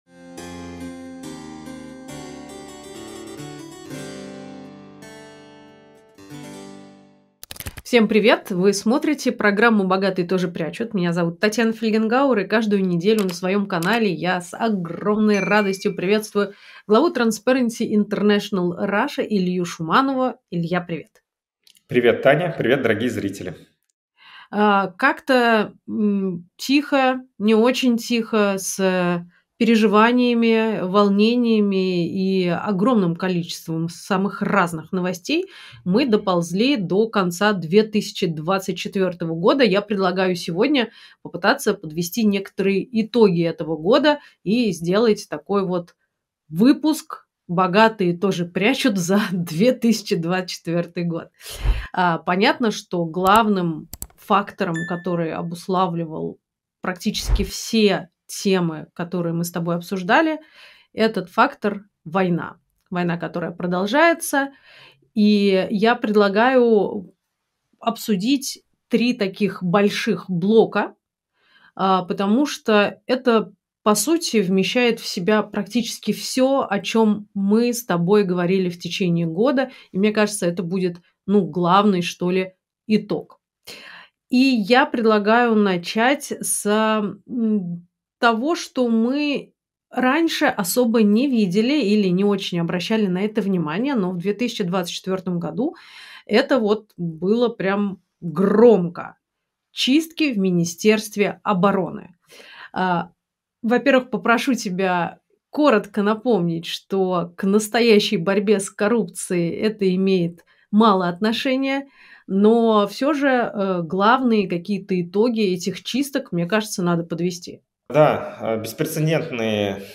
Эфир Татьяны Фельгенгауэр и Ильи Шуманова